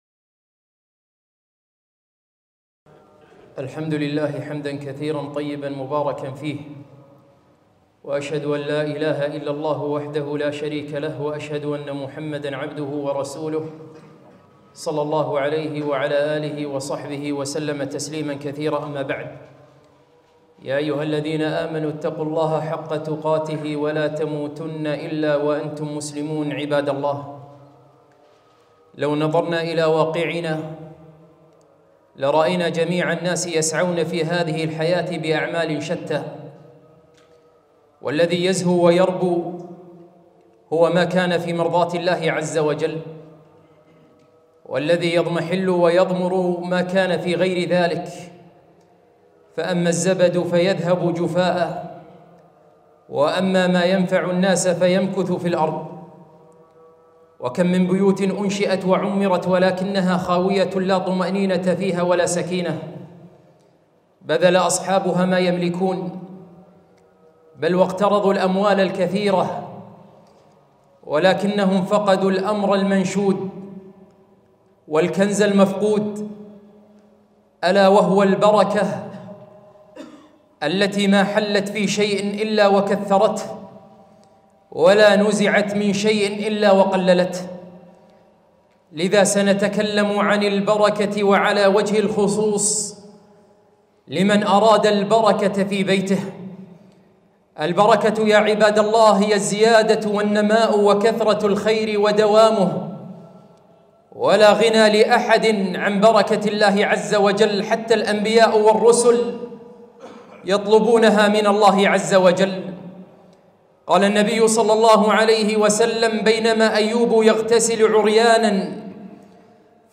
خطبة - أسباب جلب البركة في بيوتنا